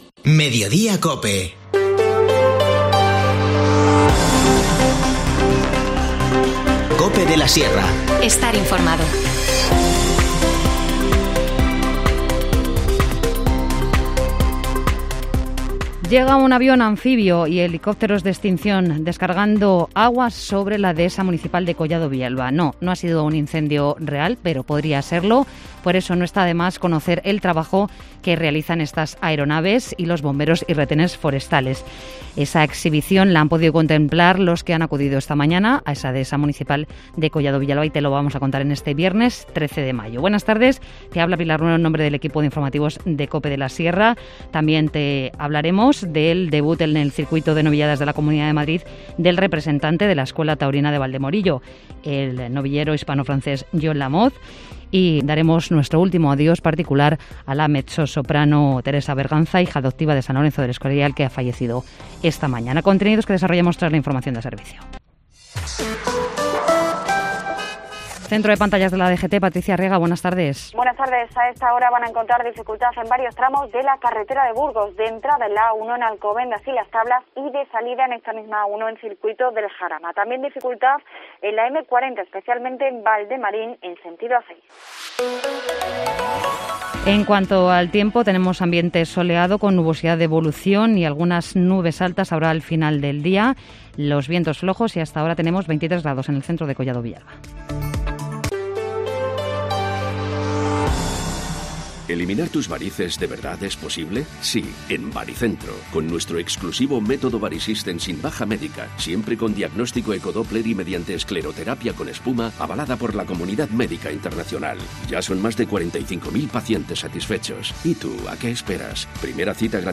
Informativo Mediodía 13 mayo